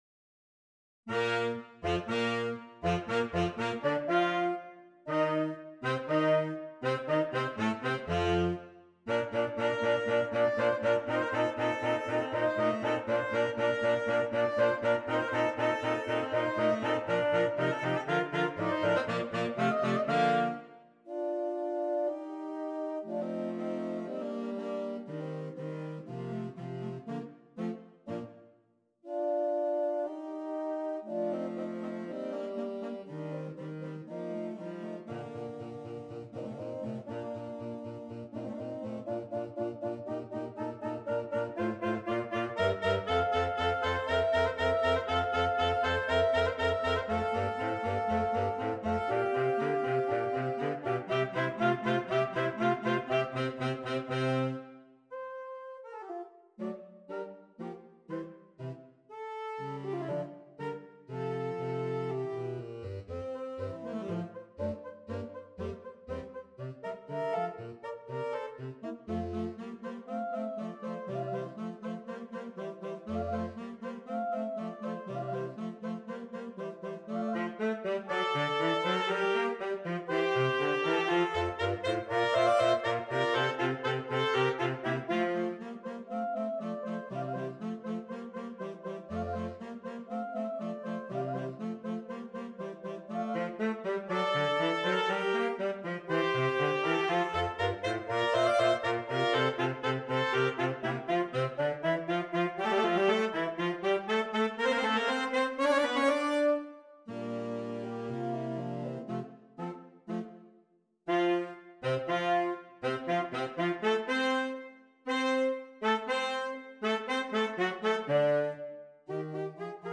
Saxophone Quartet (AATB)
All parts are interesting and exciting.